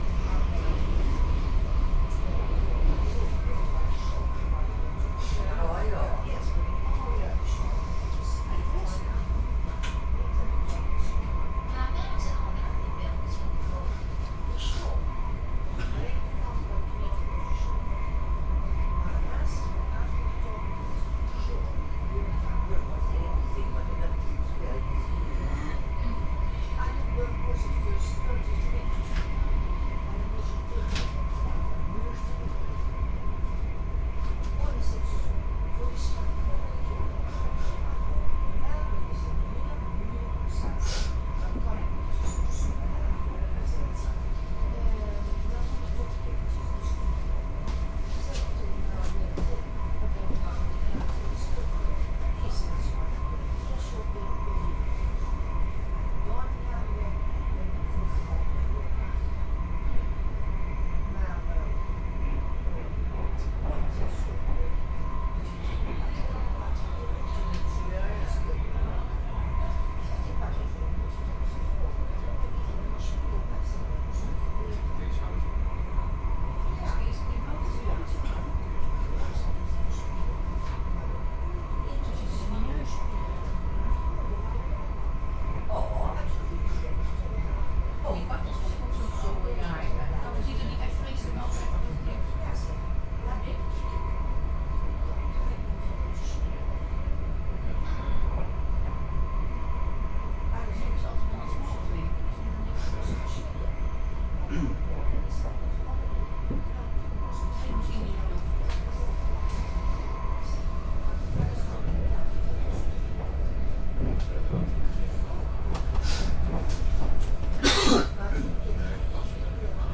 铁路 " 电动火车通过 02
描述：电动客运列车在交界处。
在建筑物之间进行现场录音（50米远），所以你会听到一些共鸣。
标签： 机车 车轮 振动 金属 乘客列车 铁路 传球 火车 隆隆声 铁路 电动 接线 噪声 铁路 骑马 轨道 现场记录 传球 电动火车 嘎嘎
声道立体声